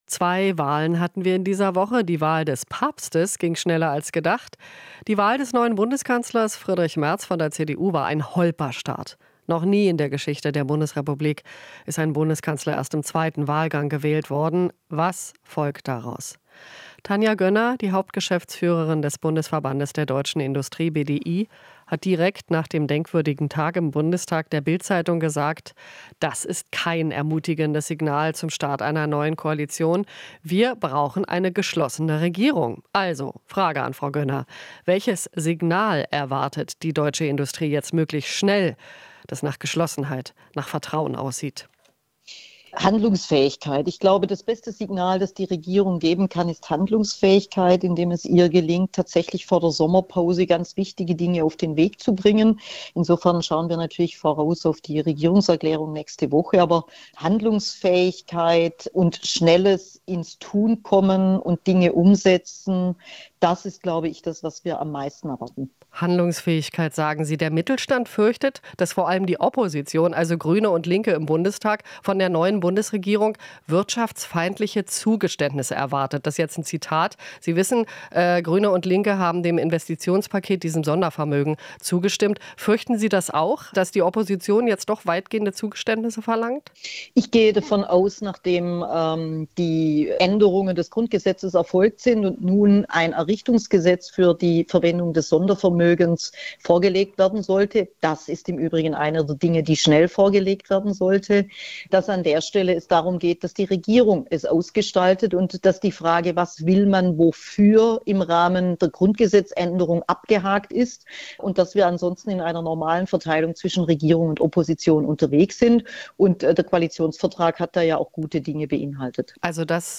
Interview - BDI-Chefin Gönner: "Erwarten handlungsfähige Regierung"